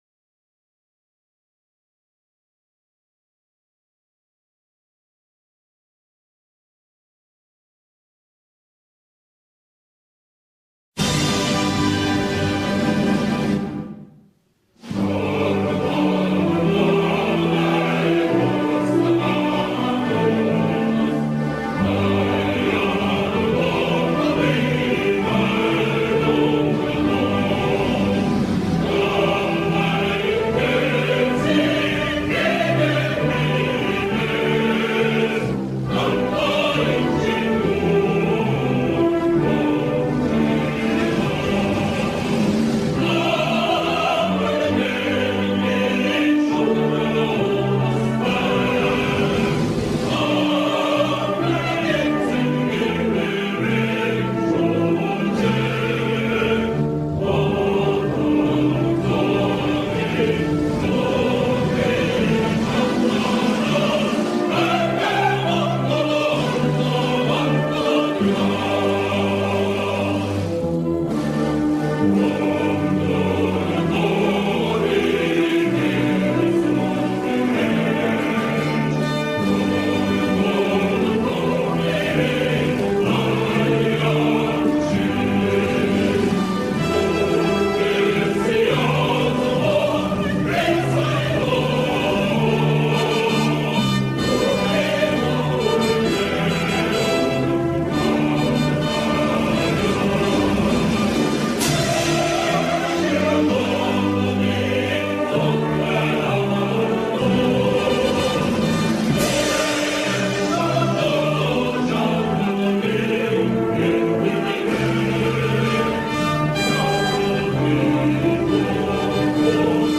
National-Anthem-Mongolia---Монгол-Улсын-төрийн-дуулал.mp3